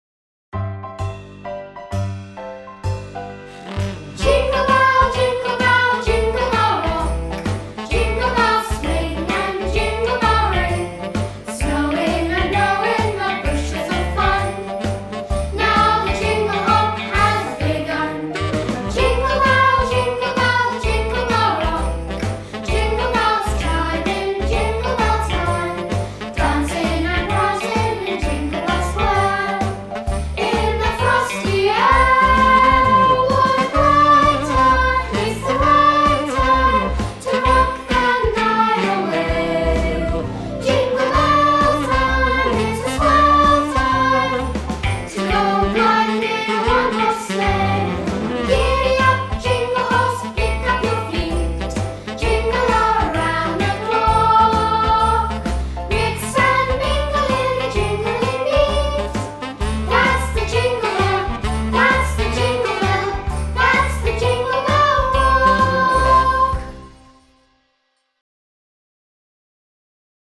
A Performance Track plays the entire song with vocals.